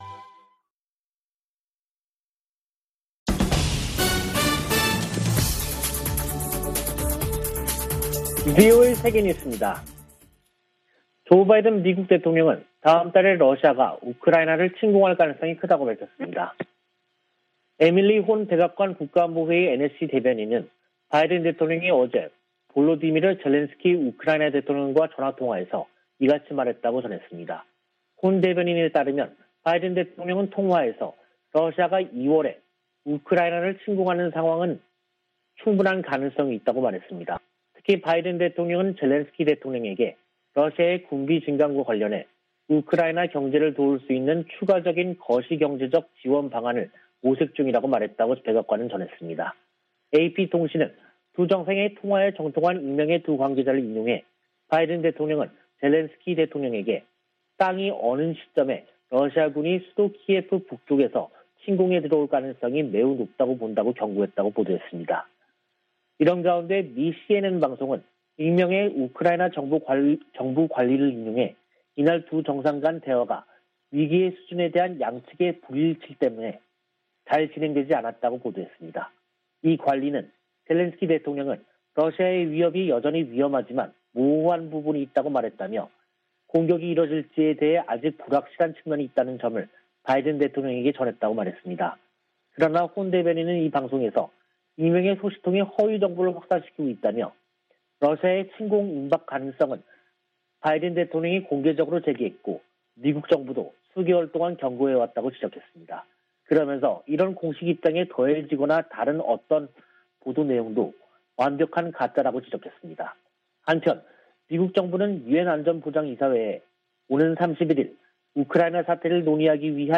VOA 한국어 간판 뉴스 프로그램 '뉴스 투데이', 2022년 1월 28일 2부 방송입니다. 북한이 27일의 지대지 전술유도탄 시험발사와 지난 25일의 장거리 순항미사일 시험발사에 각각 성공했다고 28일 공개했습니다. 미 국무부는 외교 우선 대북 접근법을 확인하면서도, 도발하면 대가를 치르게 하겠다는 의지를 분명히 했습니다. 미 국방부는 북한의 불안정한 행동을 주목하고 있다며 잇따른 미사일 발사를 '공격'으로 규정했습니다.